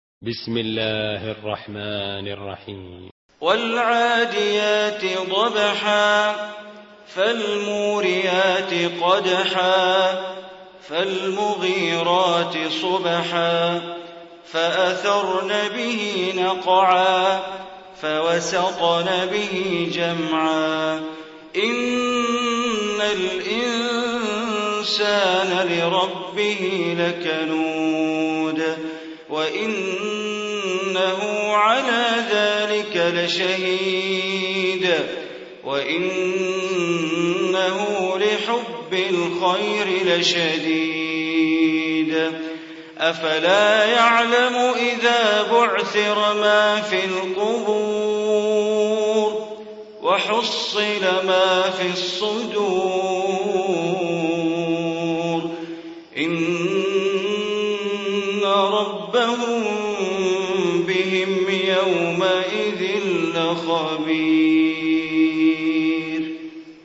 Surah Adiyat Recitation by Sheikh Bandar Baleela
Surah Al-Adiyat, listen online mp3 tilawat / recitation in Arabic recited by Imam e Kaaba Sheikh Bandar Baleela.